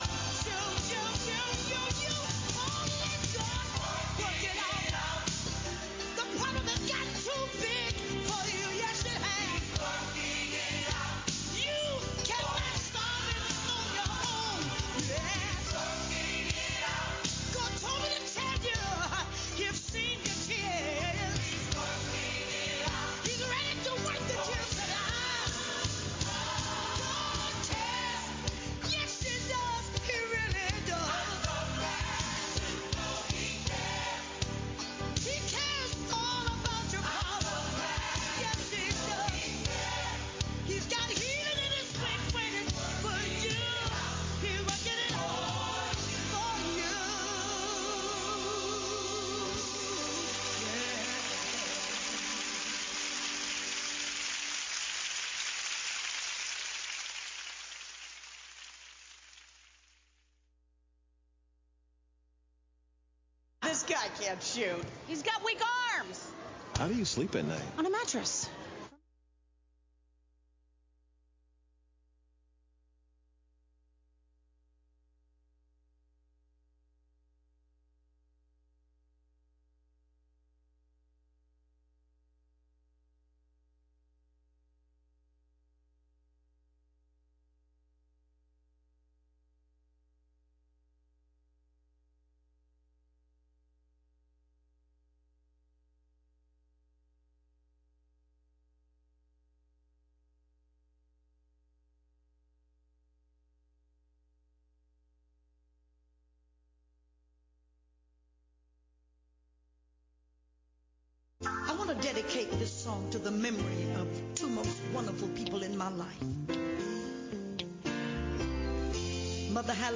7:30 A.M. Service: Rod and Staff – New Hope Missionary Baptist Church